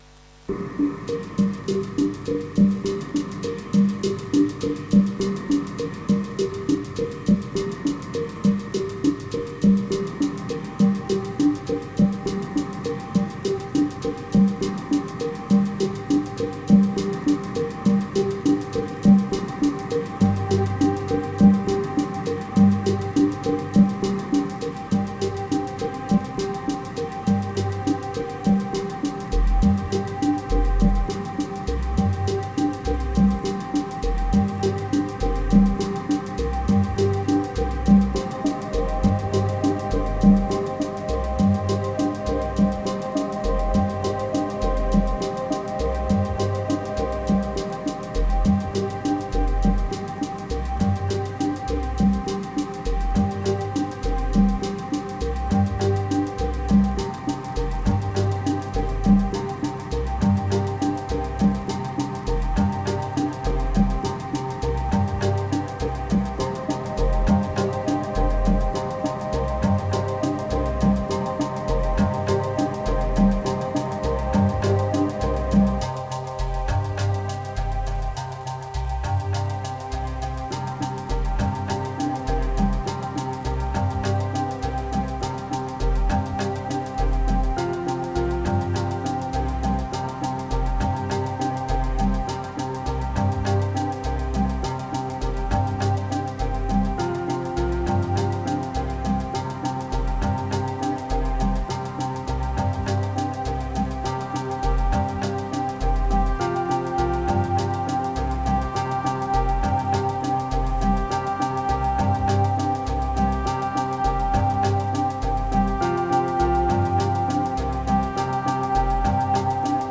1626📈 - 80%🤔 - 51BPM🔊 - 2022-09-22📅 - 1514🌟
Prise du matin avec le soleil qui se lève.